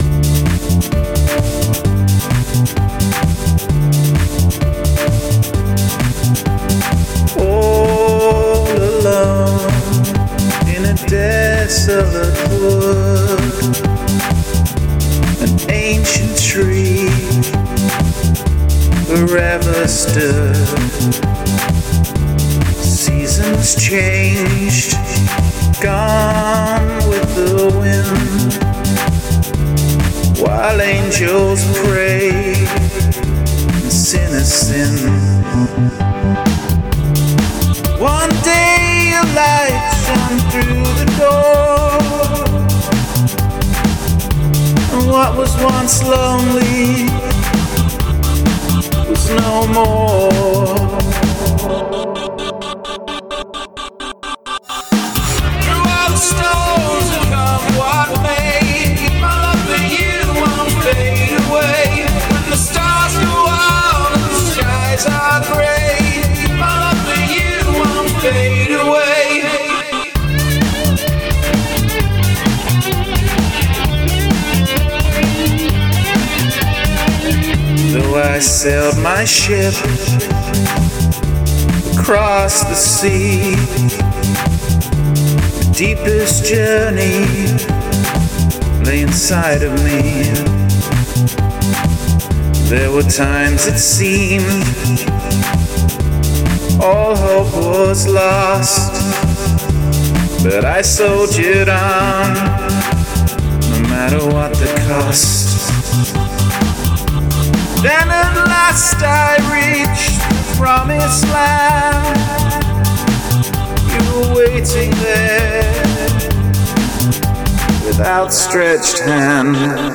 • I like the upbeat intro, the beat is catchy.